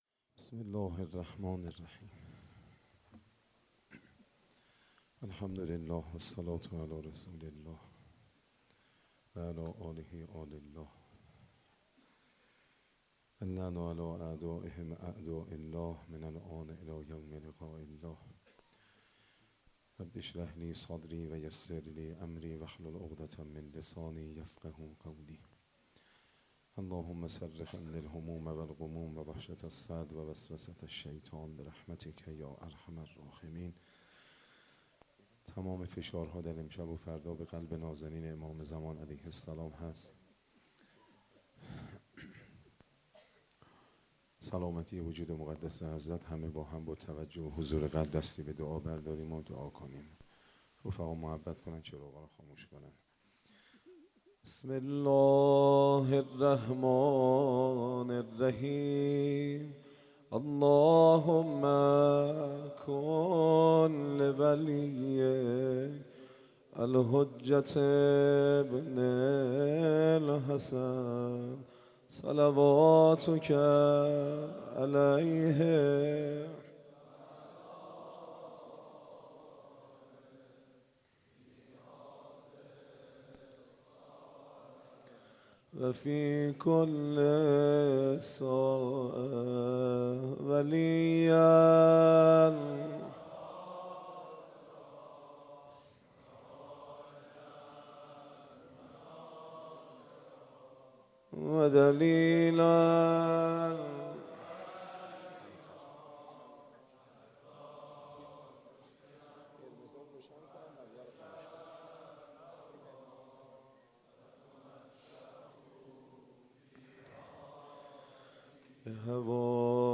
شب دهم محرم 95_سخنرانی_مسجد الهادی علیه السلام